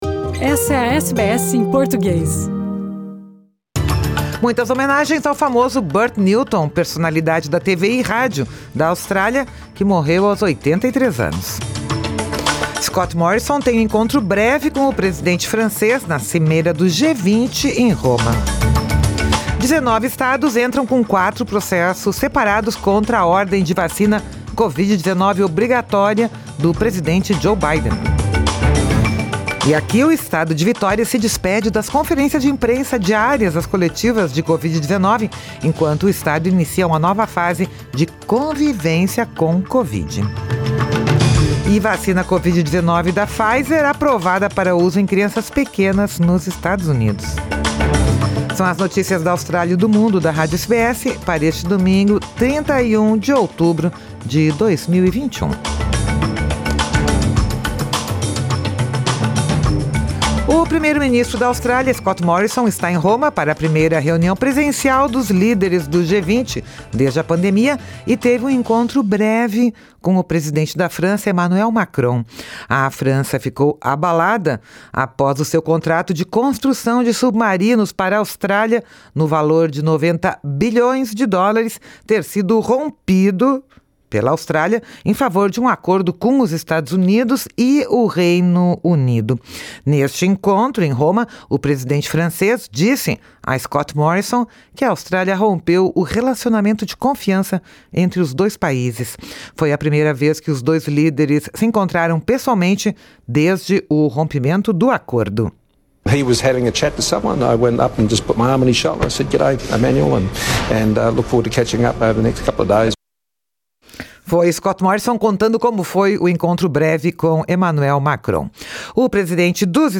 Vacina COVID-19 da Pfizer aprovada para uso em crianças pequenas nos Estados Unidos. São as notícias da Austrália e do Mundo da Rádio SBS para este domingo, 31 de outubro de 2021.